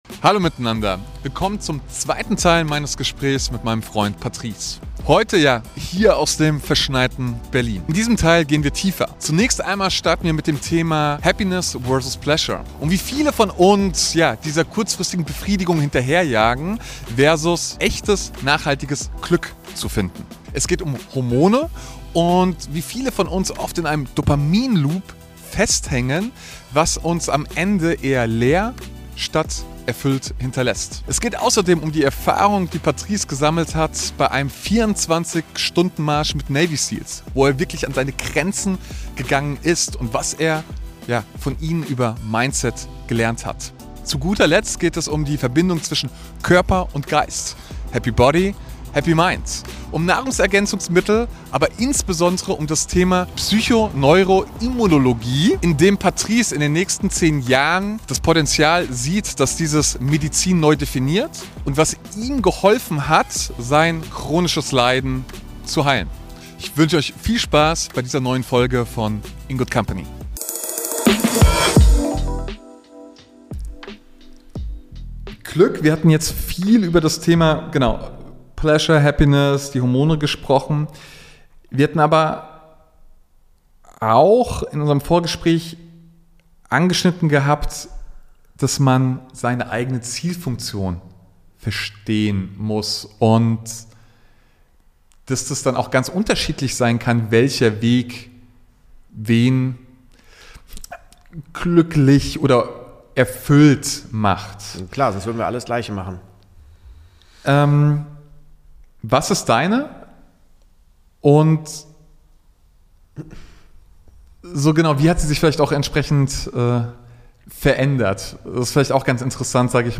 Das Gespräch bleibt nah an konkreten Erfahrungen – aus Business, Gesundheit und persönlicher Veränderung.